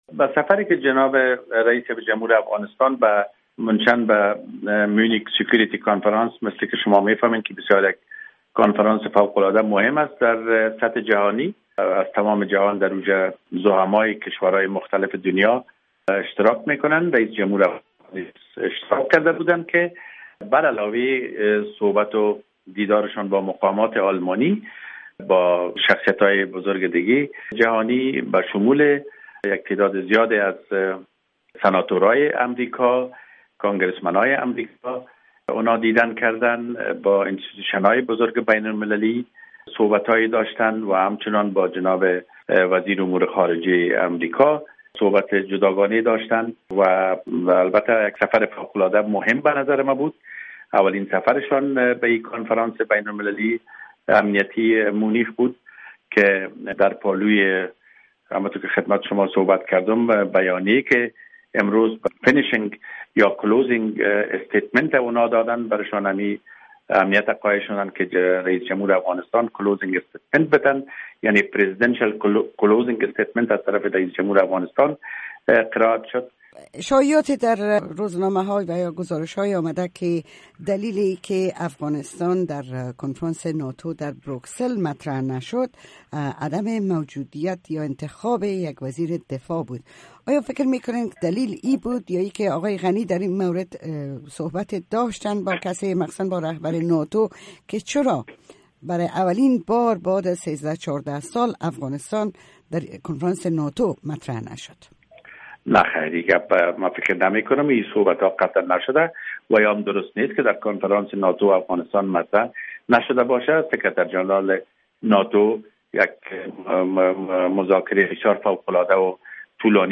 مصاحبه ها
مصاحبه با حمید صدیق، سفیر افغانستان در آلمان